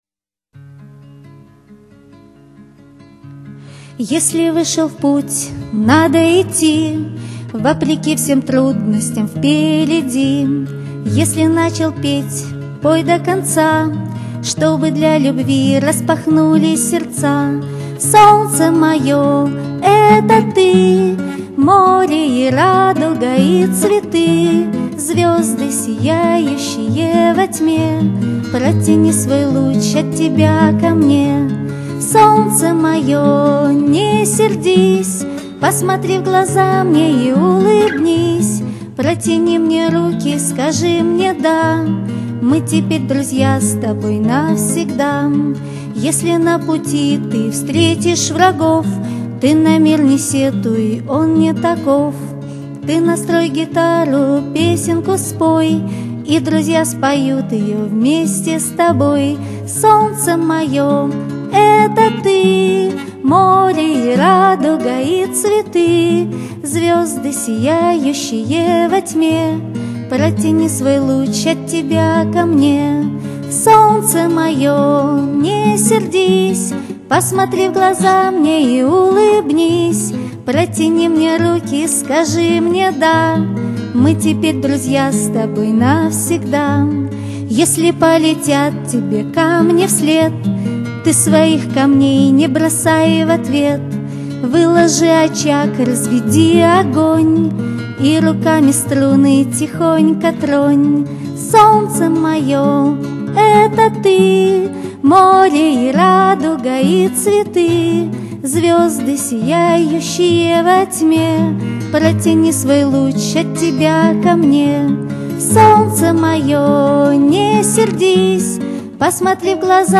В исполнении автора.